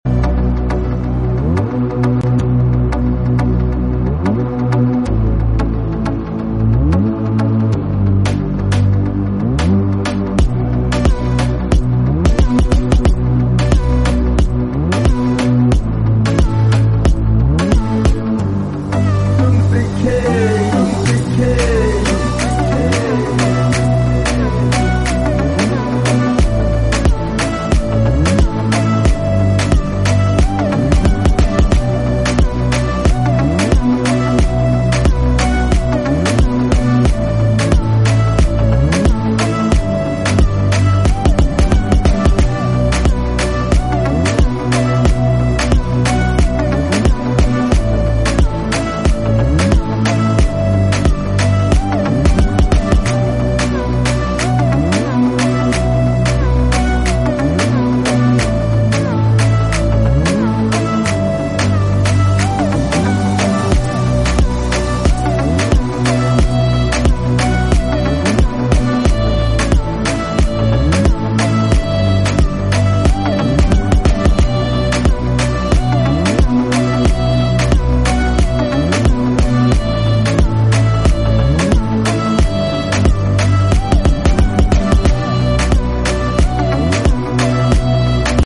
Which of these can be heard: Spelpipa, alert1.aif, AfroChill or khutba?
AfroChill